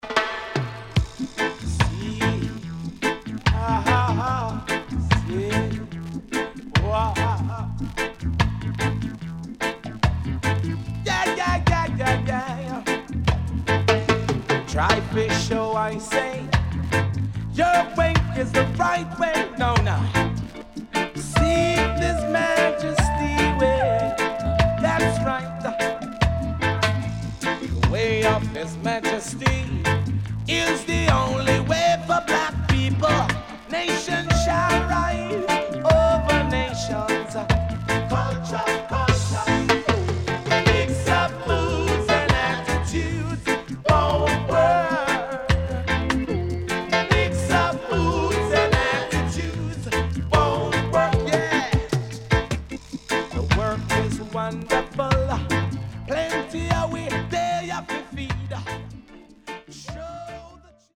SIDE A:プレス起因で少しチリノイズ入ります。